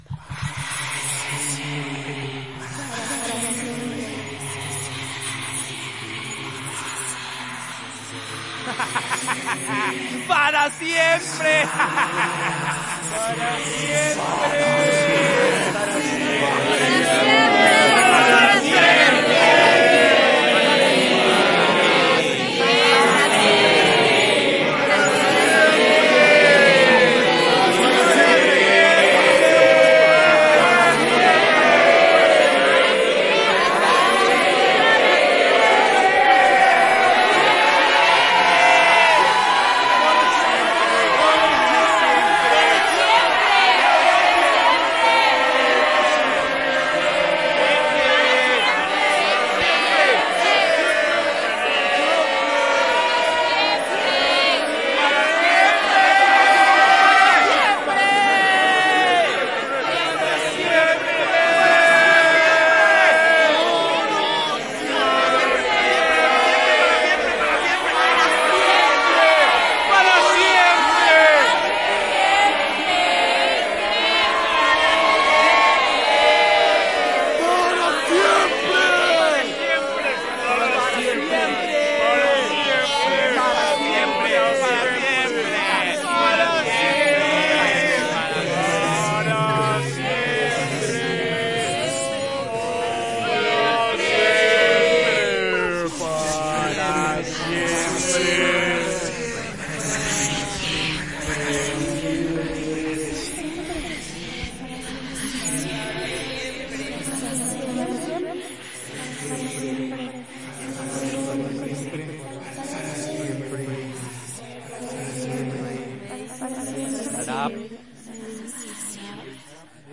描述：vocesenespañoldiciendo“para siempre”y en reversa。用西班牙语说“永远”和反向的声音。使用aringher B1和Tascam DR40录制在立体声声像盘中
Tag: demonio 声音 恐怖 西班牙语 fantasma的 恐怖 espanto 魔鬼